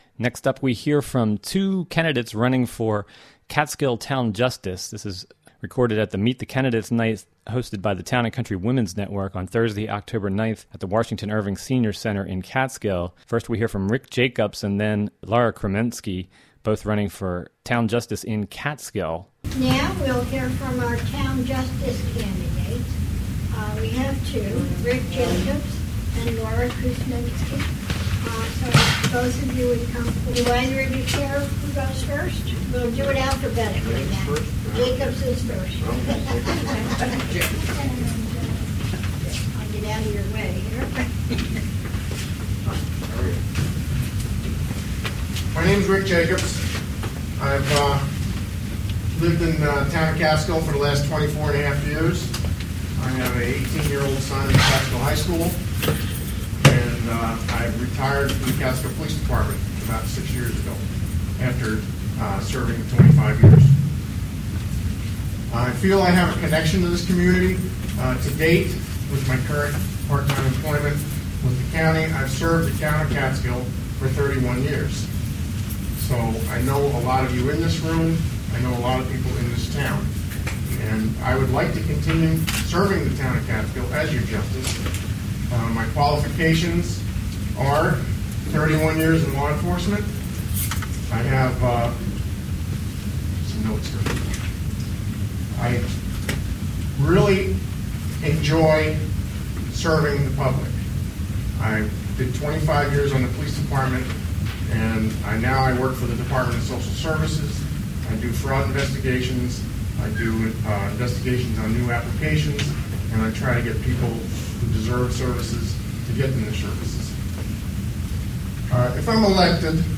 Live broadcast of candidate forum.